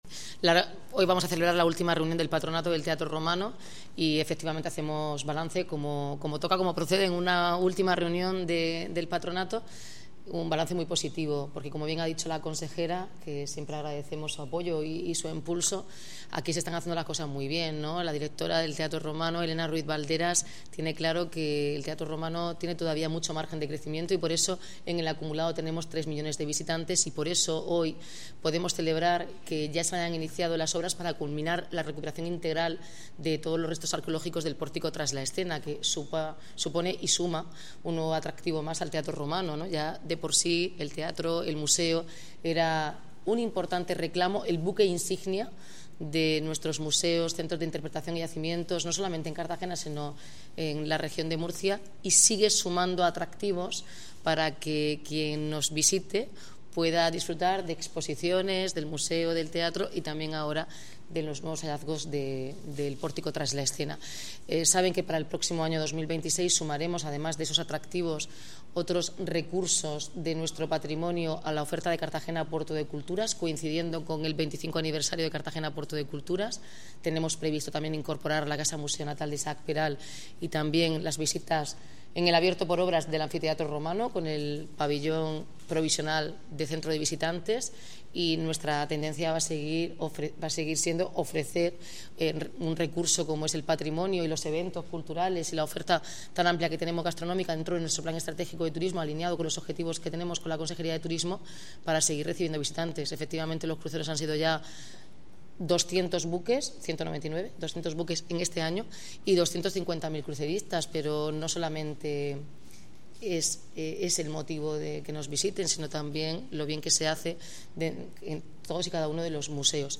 Declaraciones de la alcaldesa, Noelia Arroyo, la consejera Carmen Conesa